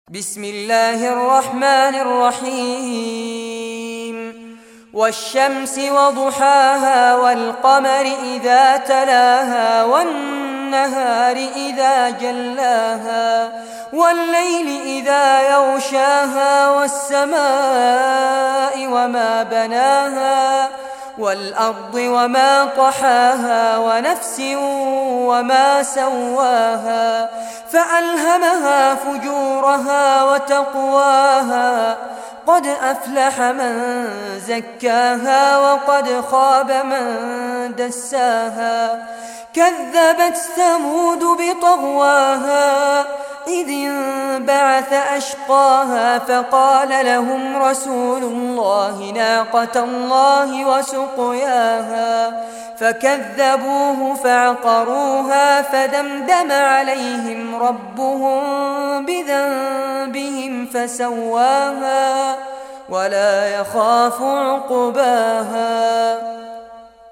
Surah Ash-Shams Recitation by Fares Abbad
Surah Ash-Shams, listen or play online mp3 tilawat / recitation in Arabic in the beautiful voice of Sheikh Fares Abbad.
91-surah-shams.mp3